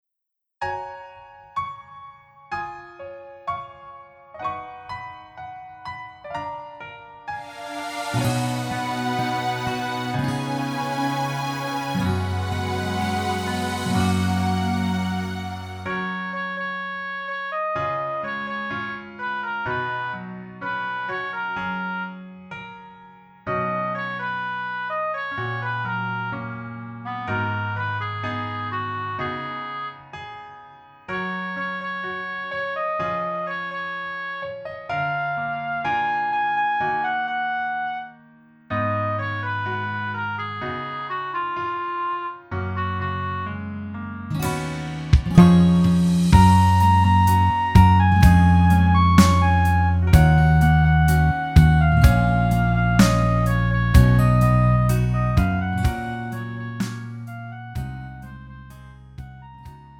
미리듣기
음정 여자키
장르 가요 구분 Pro MR